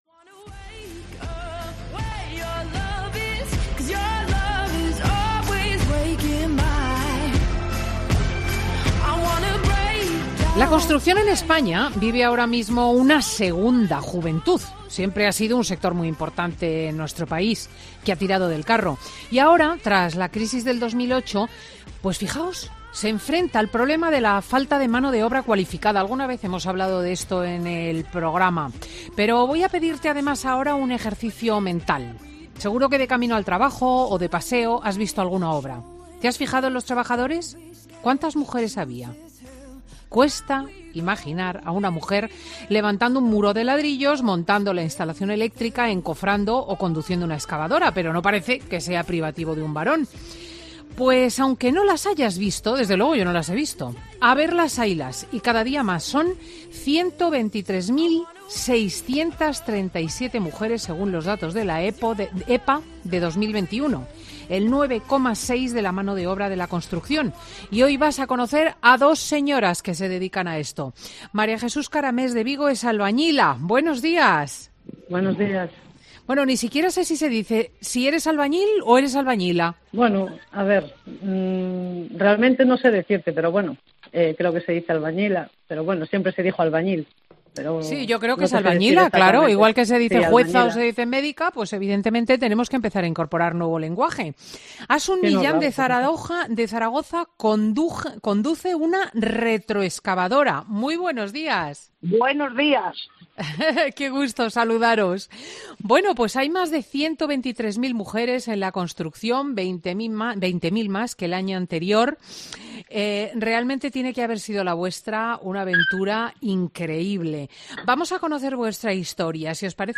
Dos mujeres dedicadas a la construcción cuentan en Fin de Semana con Cristina cómo es trabajar día a día en un sector dominado por hombres